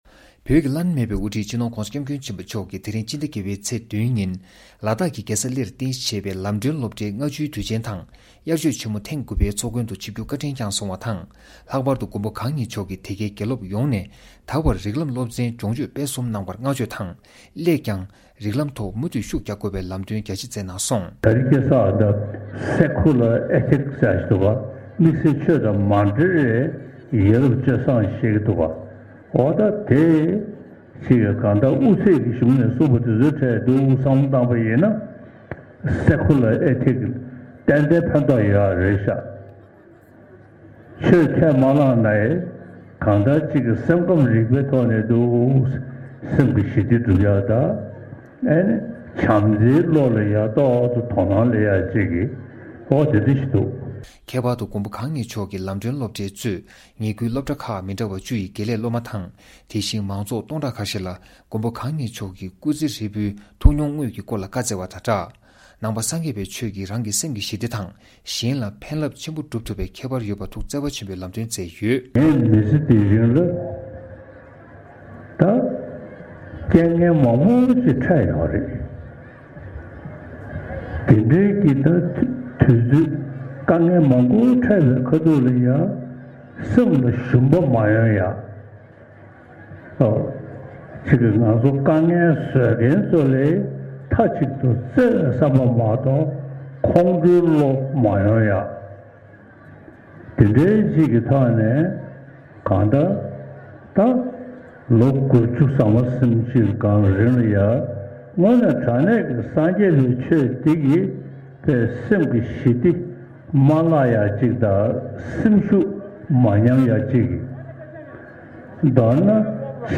སྤྱི་ནོར་གོང་ས་སྐྱབས་མགོན་ཆེན་པོ་མཆོག་གིས་ལ་དྭགས་ཀྱི་ལམ་སྒྲོན་སློབ་གྲྭའི་ལྔ་བཅུའི་དུས་ཆེན་དང་དབྱར་ཆོས་ཆེན་མོ་ཐེངས་༩པའི་ཚོགས་མགོན་དུ་ཆིབས་སྒྱུར་གྱིས་རིགས་ལམ་སློབ་ཚན་སྦྱོང་སྤྱོད་སྤེལ་གསུམ་གནང་བར་བསྔགས་བརྗོད་དང་། སླད་ཀྱང་རིགས་ལམ་ཐོག་མུ་མཐུད་ཤུགས་རྒྱག་དགོས་པའི་བཀའ་སློབ་གནང་བའི་སྐོར།